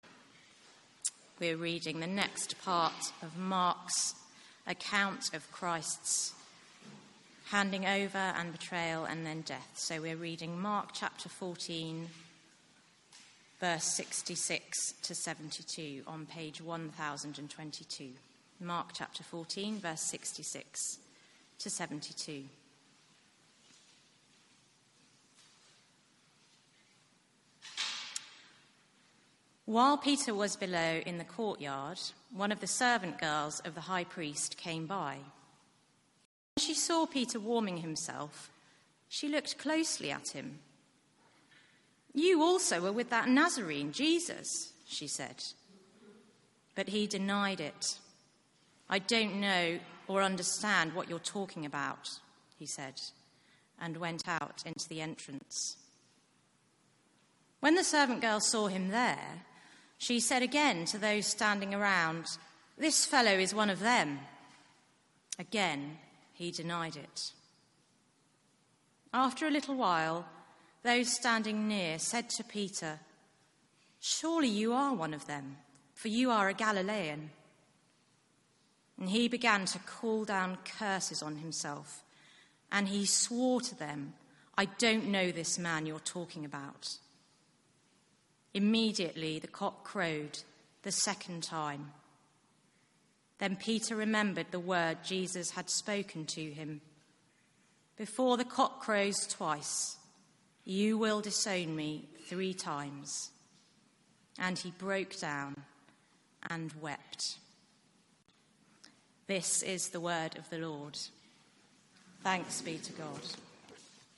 Media for 4pm Service on Sun 04th Mar 2018 16:00
ReadingPlay